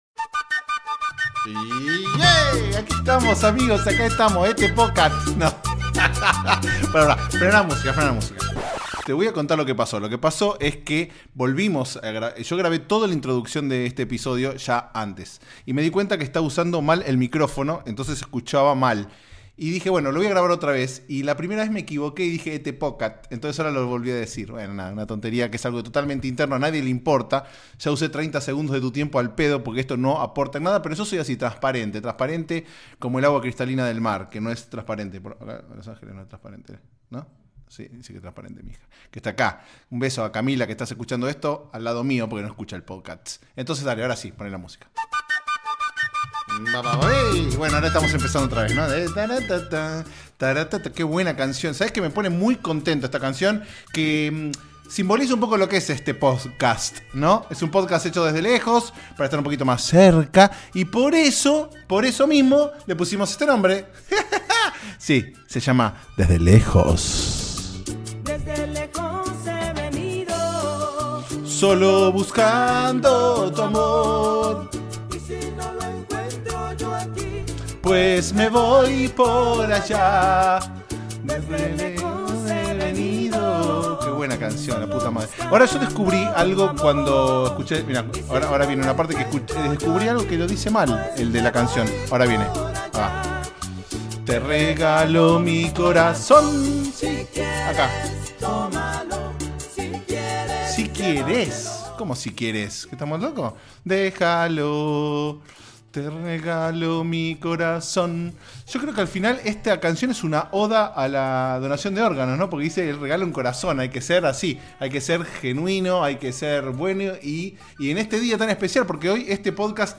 Episodio grabado íntegramente en el Día de Acción de Gracias (aka Thanksgiving), como si eso fuera importante... ¿no?
Cantamos, reímos y nos emocionamos un toque.